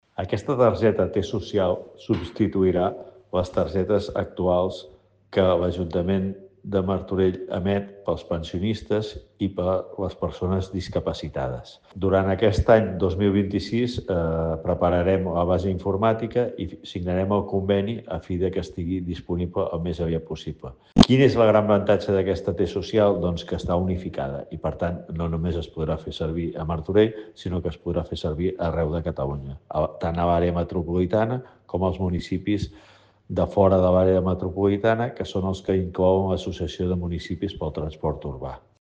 Lluís Sagarra, regidor de Mobilitat